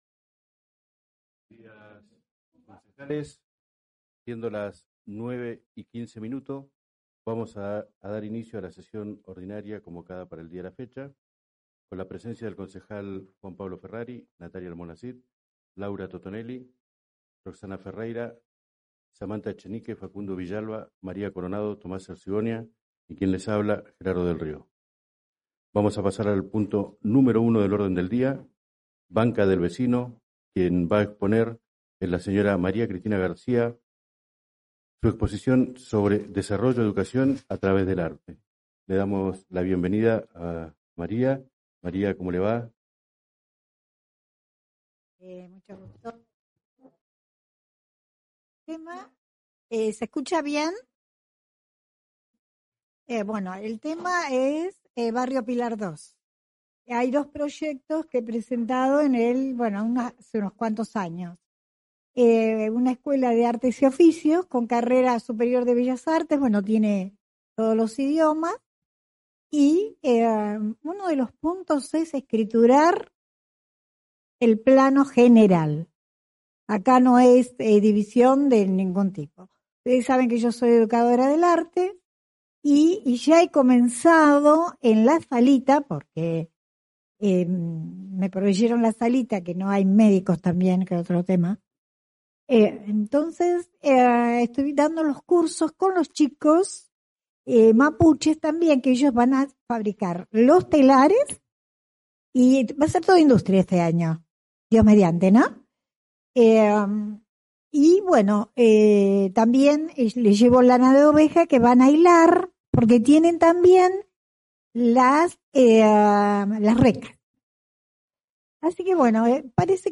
Carácter de la Sesión: Ordinaria. Lugar de realización : Sala Francisco P. Moreno, Concejo Municipal, San Carlos de Bariloche, Provincia de Río Negro, República Argentina.